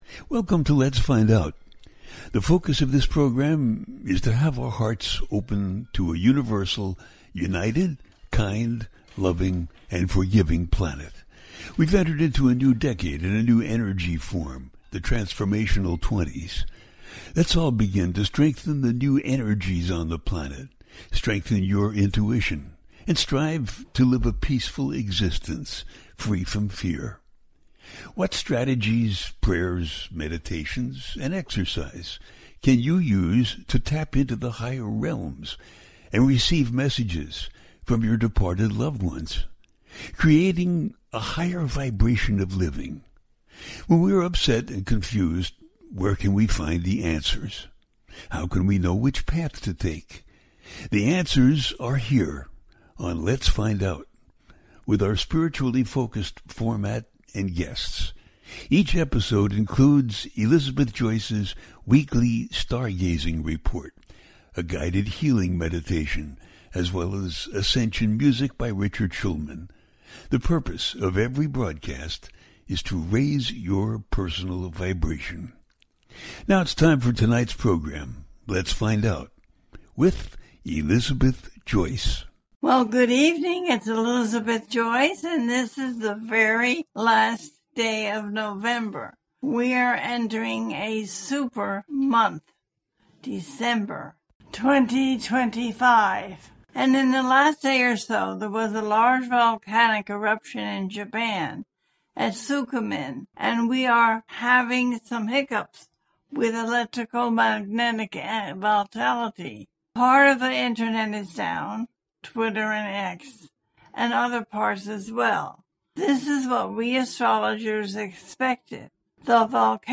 Full Super Moon In Gemini and The Closing Of An Era - A teaching show